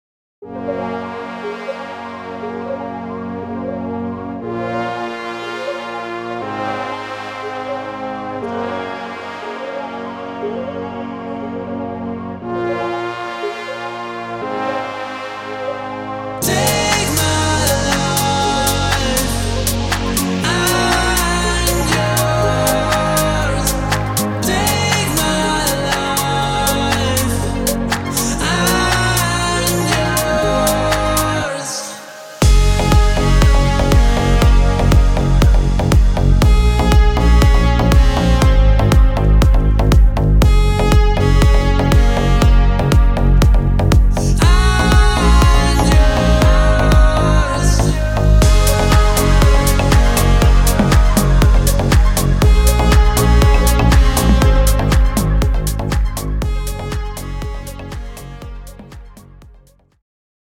Inspired by current mainstream Pop.